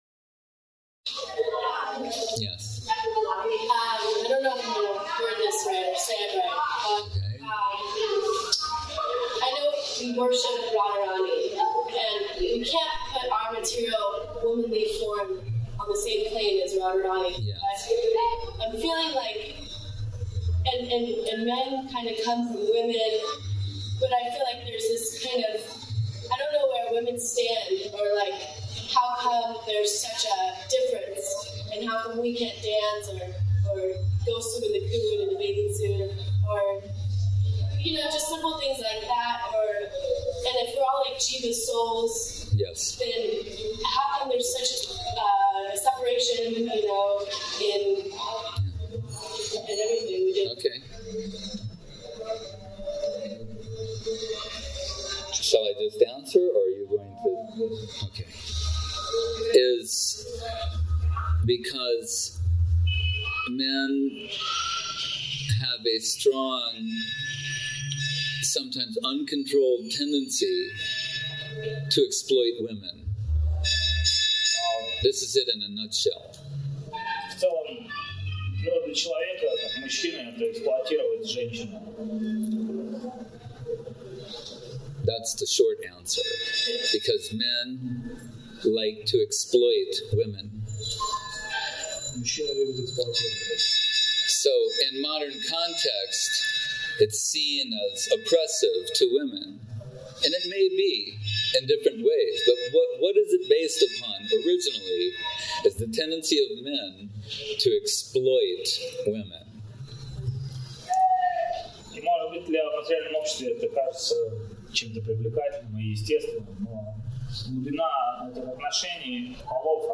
Place: SCSMath Nabadwip